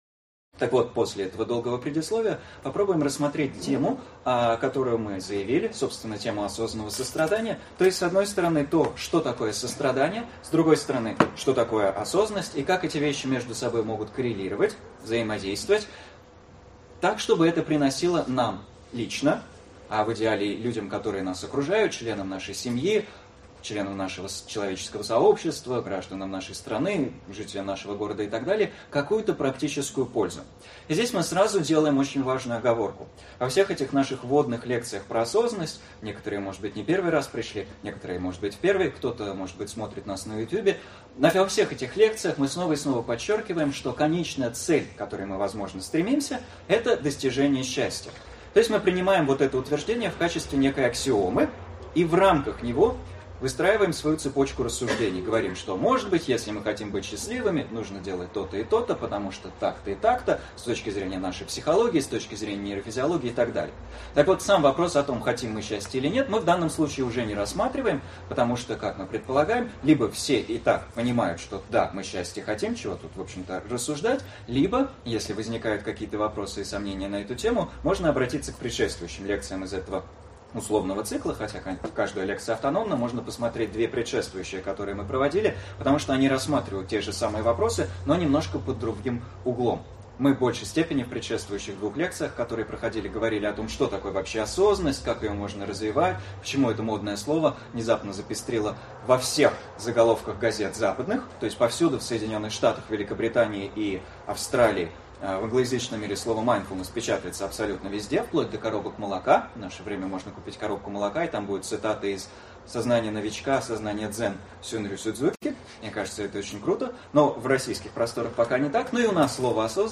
Аудиокнига Осознанное сострадание | Библиотека аудиокниг